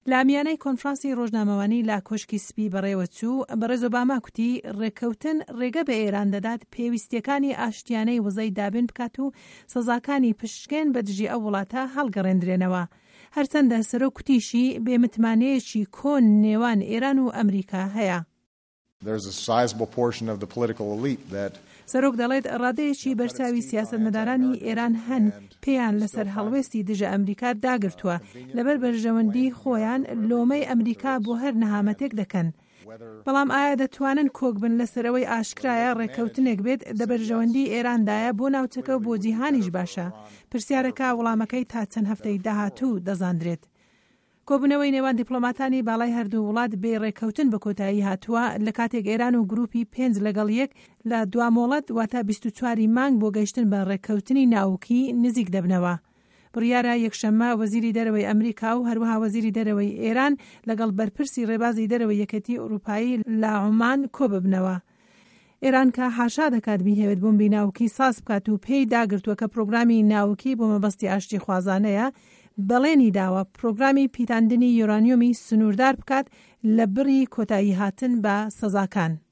ڕاپـۆرتی سه‌رۆک ئۆباما - ئێران